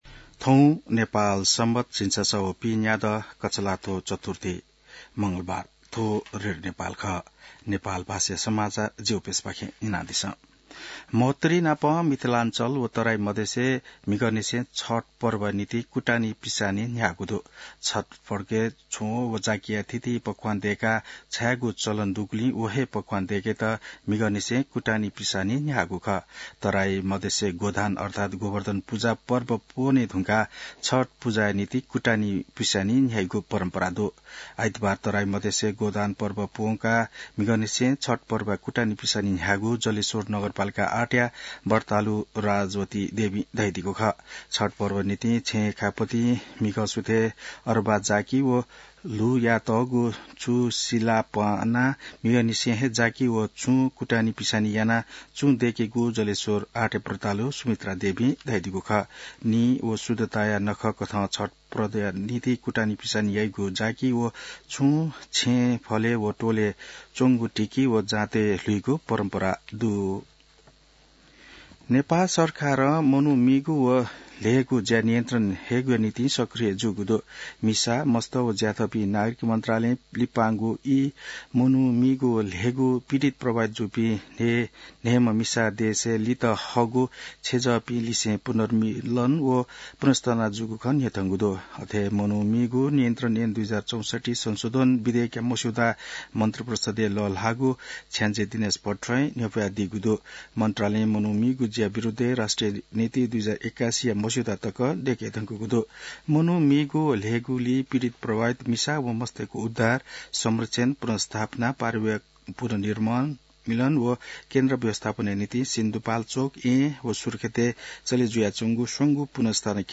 नेपाल भाषामा समाचार : २१ कार्तिक , २०८१